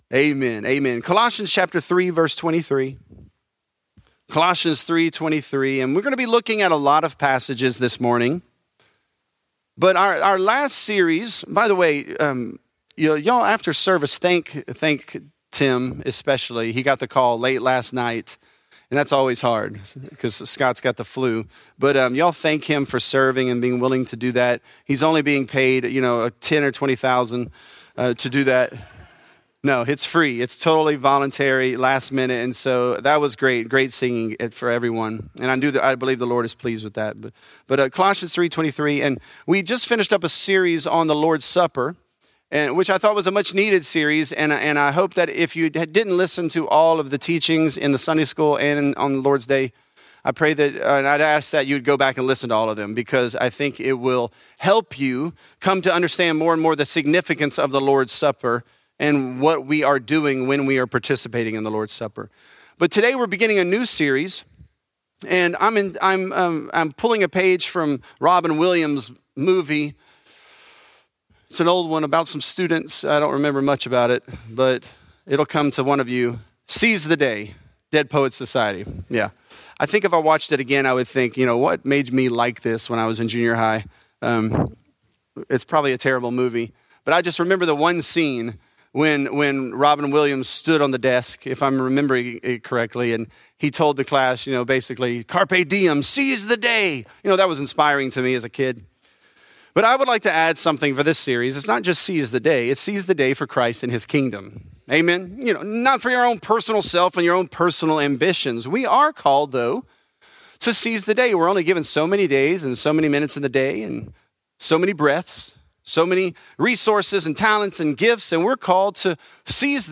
Seize The Day: Our Full Reward | Lafayette - Sermon (Colossians 3)